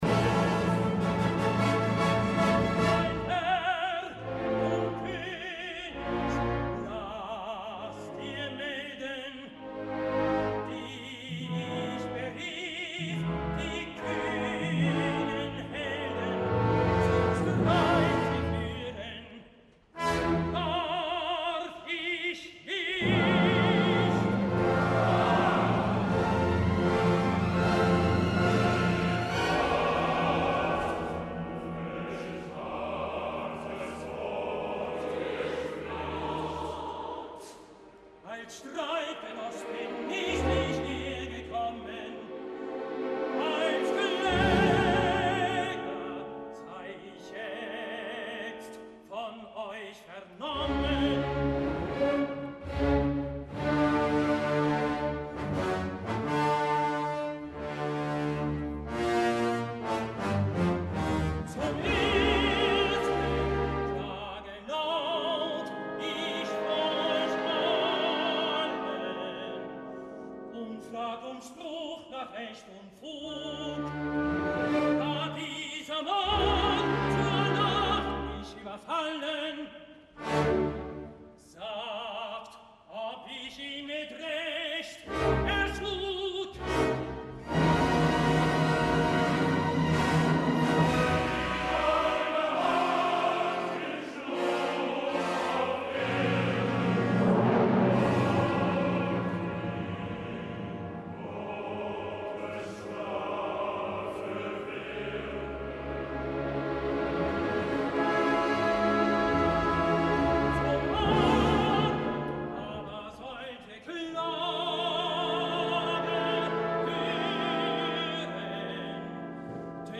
Ahir i dins del Bayreuther Festspiele, varem tenir la cita amb Lohengrin, una cita que seguint el nivell de les altres òperes escoltades enguany decep en la vessant vocal, tot i que després del Siegfried de dimecres, ahir si més no podíem apreciar cantants que ho fan bé, quelcom que no hauria de ser mai una raresa.
El director musical continua sent el mateix que va estrenar la producció, Andris Nelsons, que potser m’ho ha semblant en a mi i per tant hauré de fer un repàs de les edicions dels festivals anteriors per contrastar-ho, però aquest any m’ha semblat més lent i fins i tot en el segon acte excessivament morós, tant que m’ha fet caure el interès i el ritme narratius, tot i que ha fet que altres passatges, sobretot els concertants, brillessin amb precisió gràcies a una orquestra i cor que cada dia es superen a si mateixos.